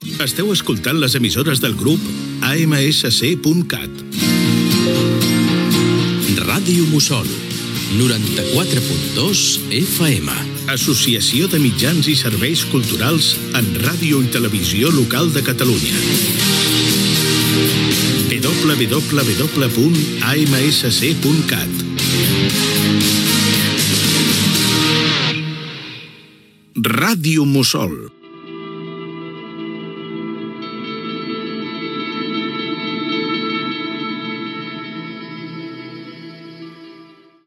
Identificació de l’Associació de Mitjans i Serveis Culturals en Radio i Televisió local de Catalunya i de l'emissora.
FM